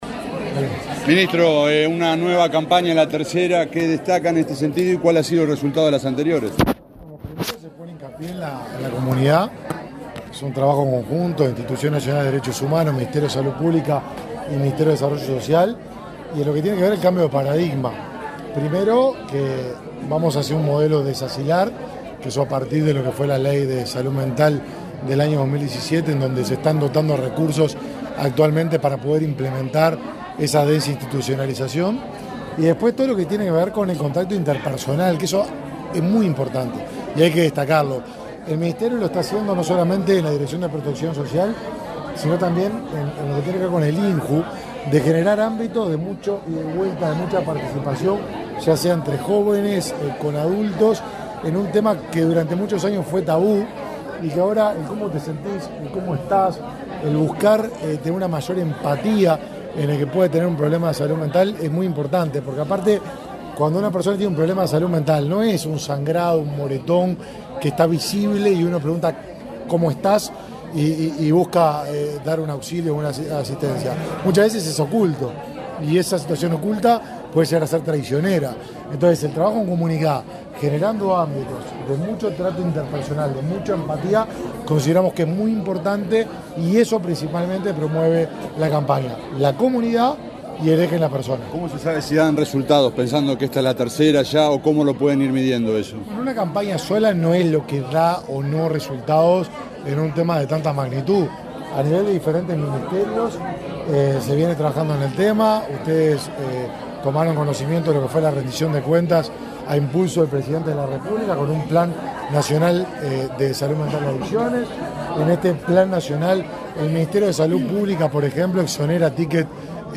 Declaraciones del ministro de Desarrollo Social, Martín Lema
Este lunes 9, en Montevideo, el ministro de Desarrollo Social, Martín Lema, dialogó con la prensa, luego de participar en el lanzamiento de la campaña